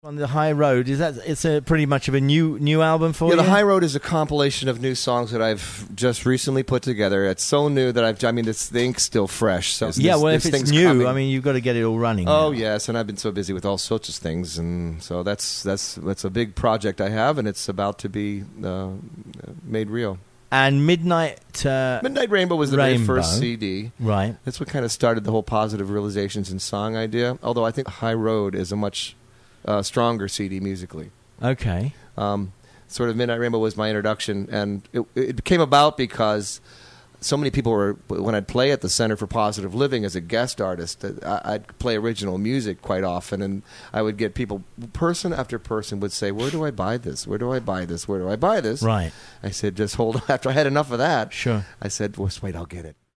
Interview_3.mp3